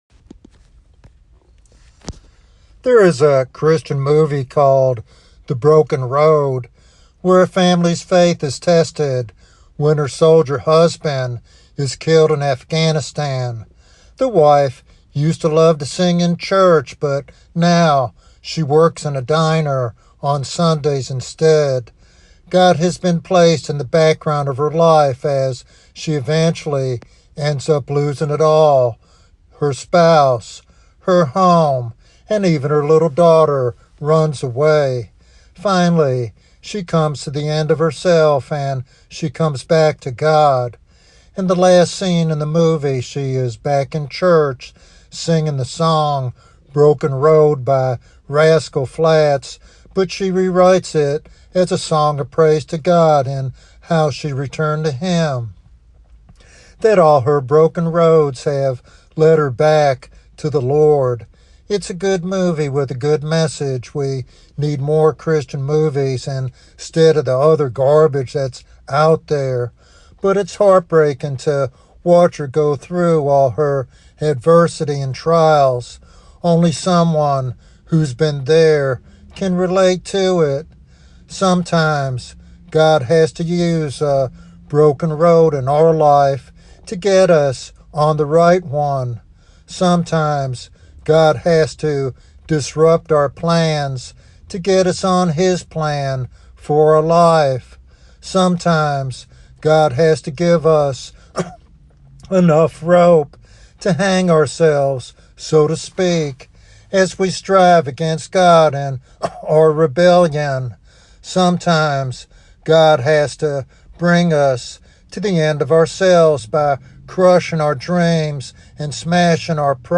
In this devotional sermon